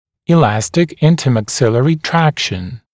[ɪ’læstɪk ˌɪntəmæk’sɪlərɪ ‘trækʃn] [и’лэстик ˌинтэмэк’силэри ‘трэкшн] межчелюстная эластичная тяга